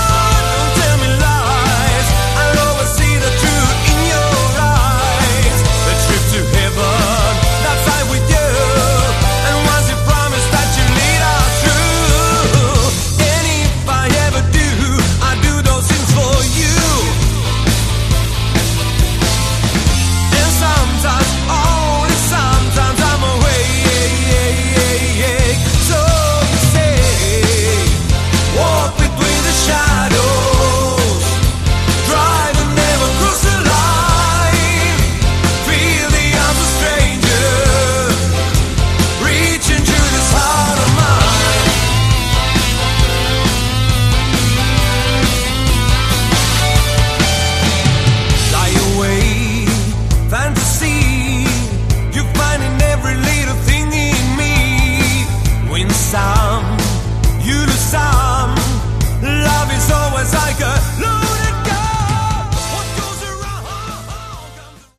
Category: Melodic Hard Rock
Guitar, Vocals
Drums
Bass
Keyboards, Vocals